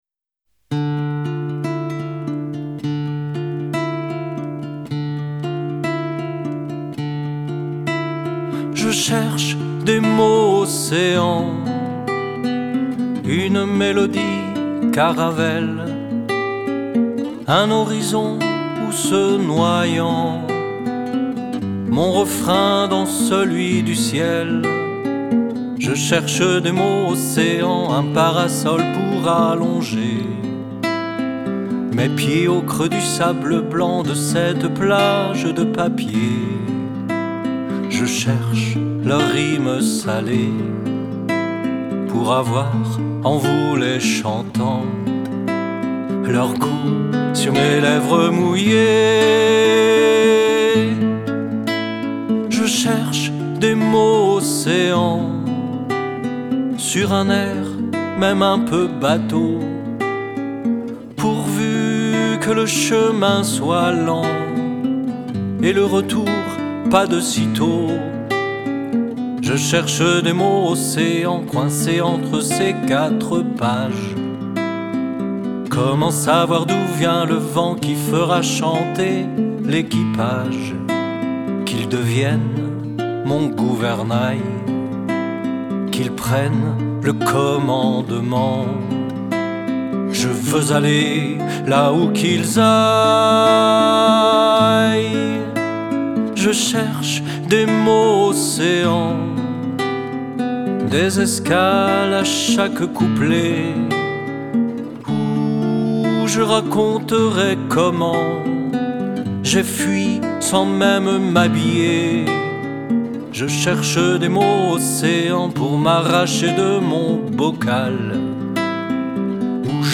guitare et piano
clarinette
chant
vielle à roue
accordéon
violoncelle
violon